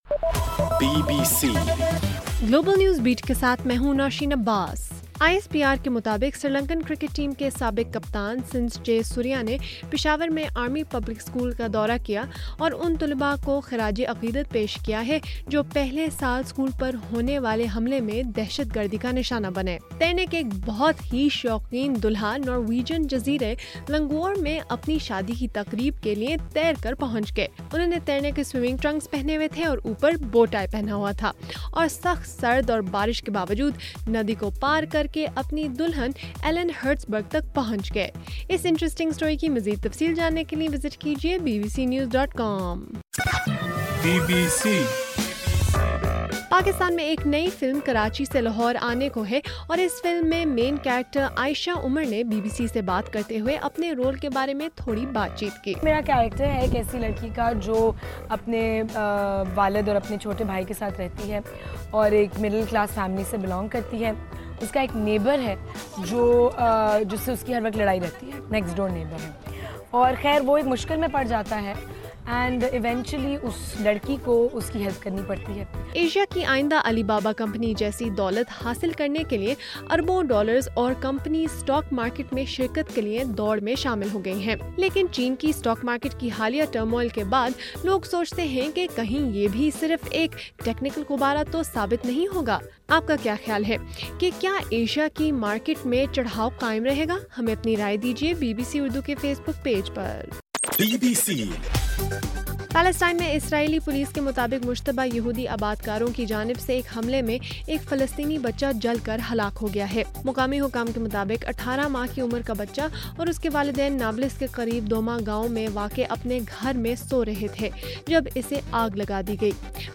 جولائی 31: رات 11 بجے کا گلوبل نیوز بیٹ بُلیٹن